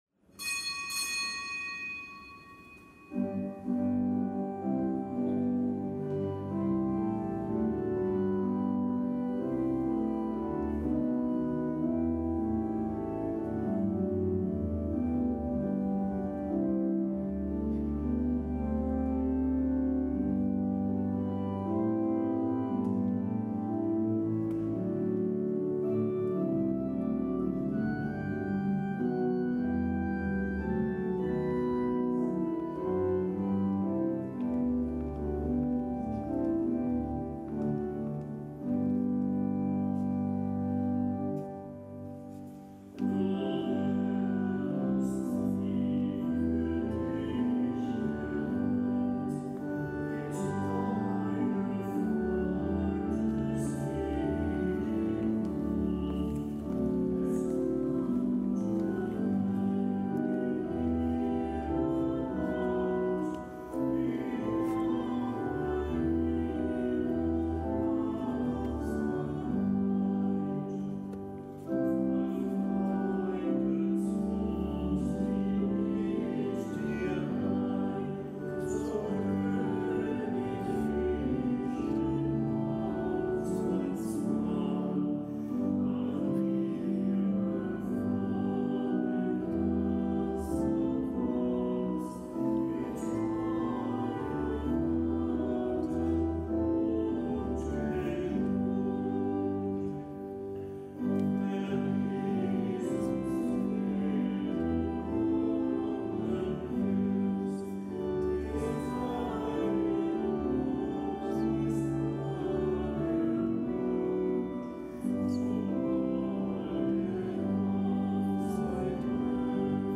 Kapitelsmesse am Gedenktag der heiligen Teresa von Ávila
Kapitelsmesse aus dem Kölner Dom am Gedenktag der heiligen Theresia von Jesus (von Ávila), Ordensfrau, Kirchenlehrerin. Zelebrant: Weihbischof Dominikus Schwaderlapp.